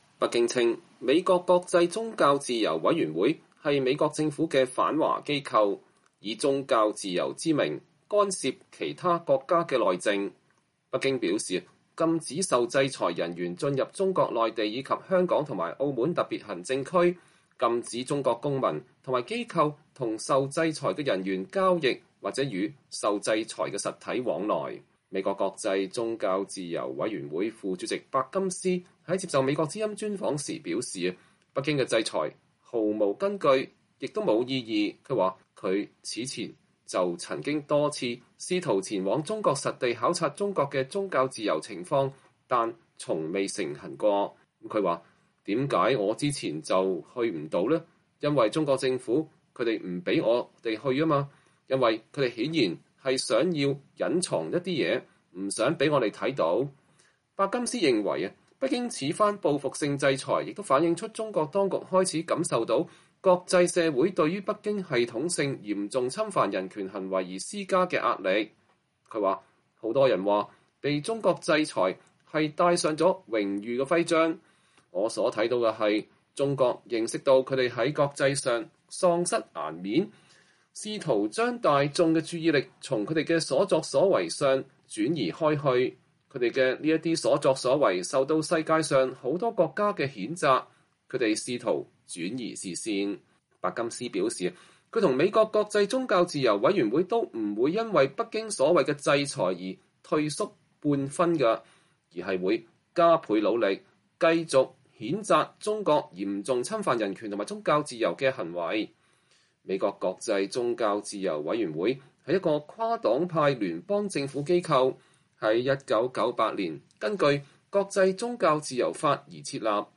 專訪美國宗教自由委員會副主席伯金斯：無懼北京制裁，繼續為中國人民發聲